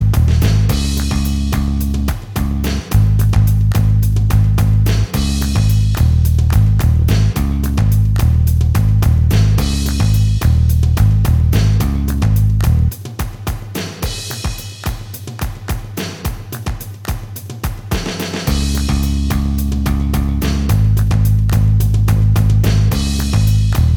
Minus Guitars Indie / Alternative 2:55 Buy £1.50